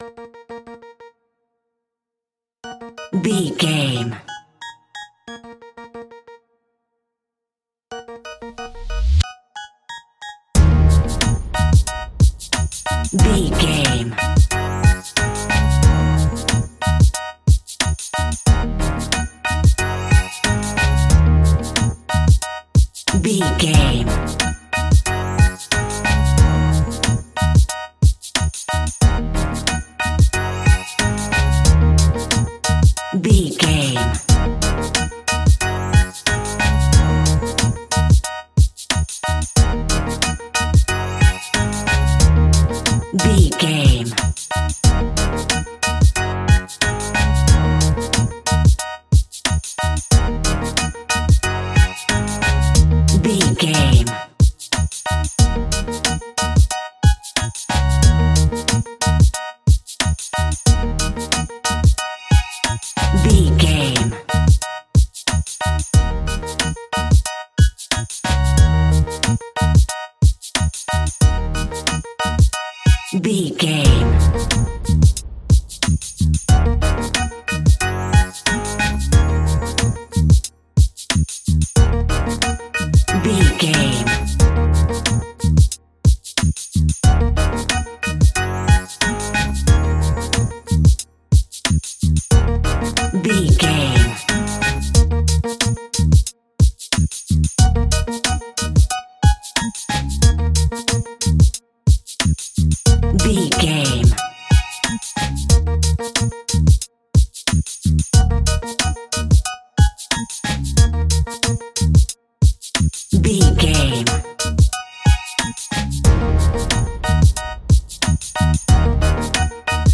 Hip hop for shaking.
Aeolian/Minor
E♭
instrumentals
chilled
laid back
groove
hip hop drums
hip hop synths
piano
hip hop pads